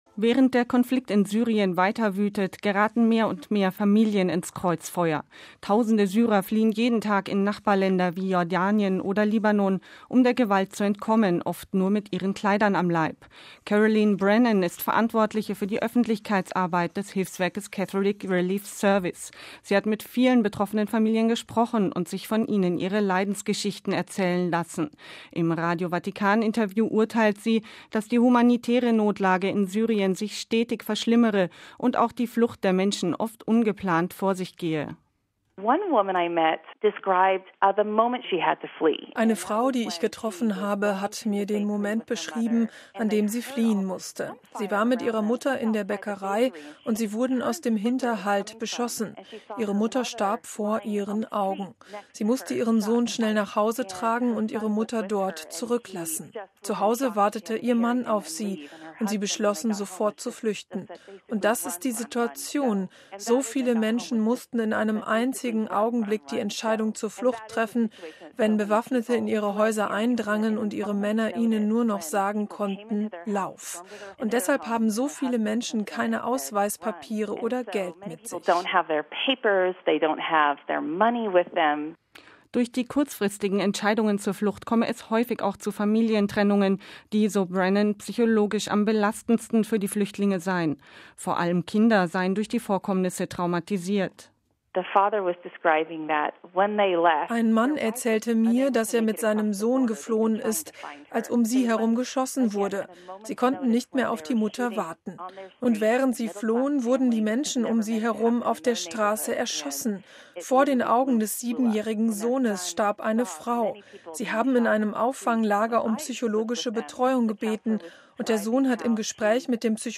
Im Radio Vatikan-Interview urteilt sie, dass die humanitäre Notlage in Syrien sich stetig verschlimmere und auch die Flucht der Menschen oft ungeplant vor sich gehe: